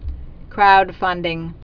(kroudfŭndĭng)